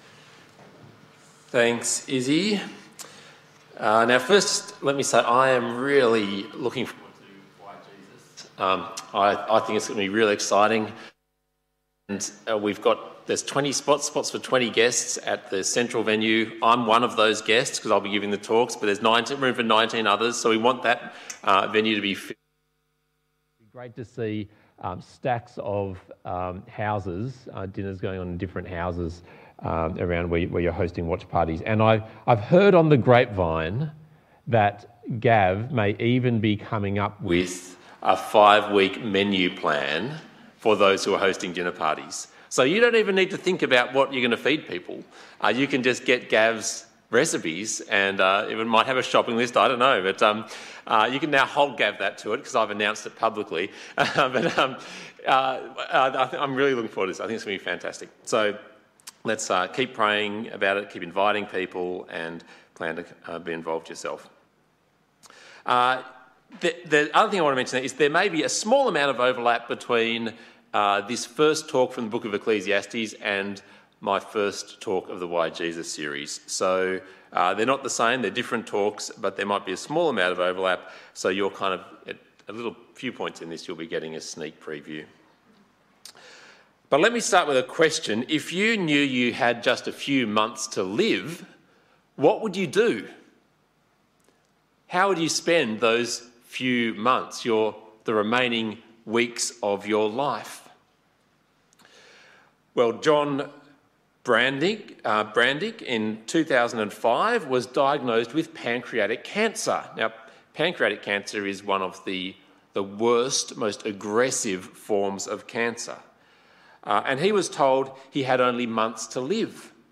Passage: Ecclesiastes 1-2 Talk Type: Bible Talk